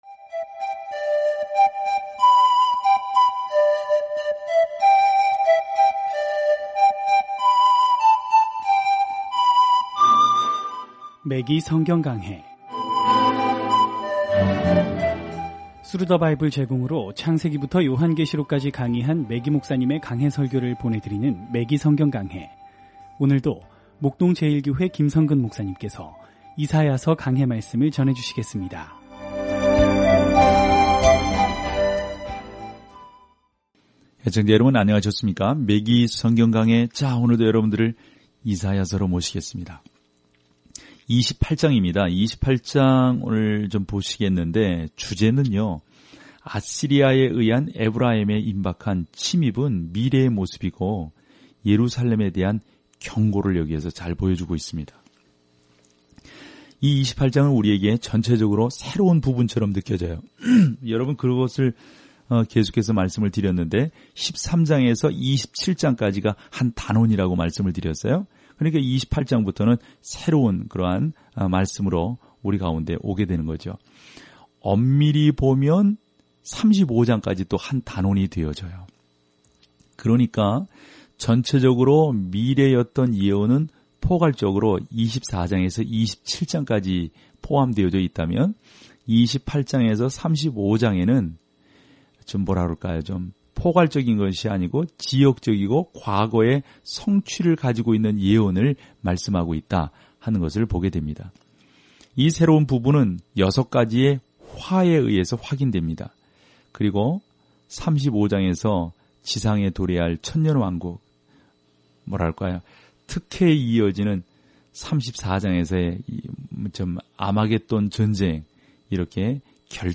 말씀 이사야 28 이사야 29:1-8 20 묵상 계획 시작 22 묵상 소개 “다섯 번째 복음”으로 불리는 이사야는 정치적 적들이 유다를 점령할 암울한 때에 “많은 사람의 죄를 담당”할 왕과 종을 묘사하고 있습니다. 오디오 공부를 듣고 하나님의 말씀에서 선택한 구절을 읽으면서 매일 이사야서를 여행하세요.